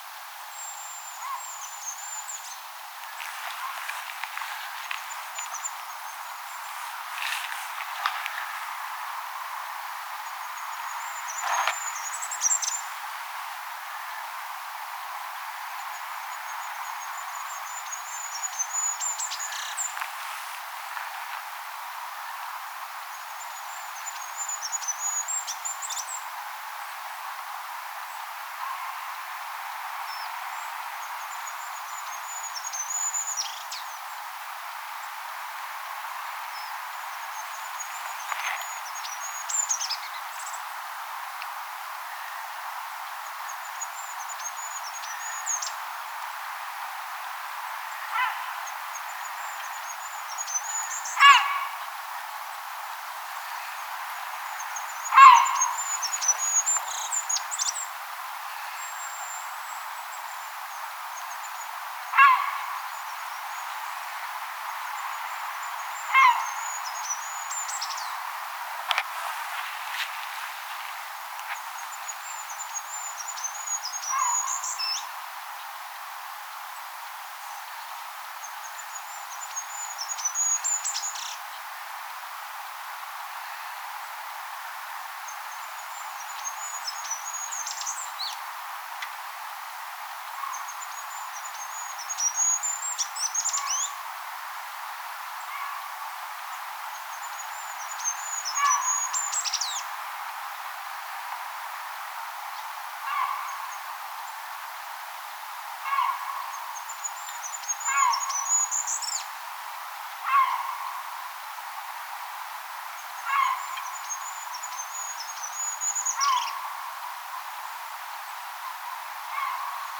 hippiäinen laulaa kanadanhanhiniemen kärjessä
Kaksi hippiäistä lauloi siinä.
hippiainen_laulaa_kanadanhanhiniemen_karjessa.mp3